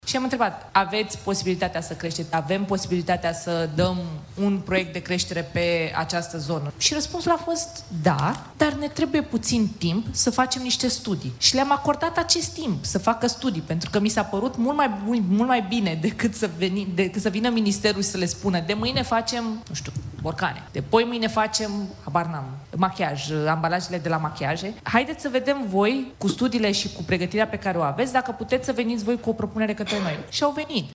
Ministrul Mediului, Diana Buzoianu: „Le-am acordat acest timp să facă studii”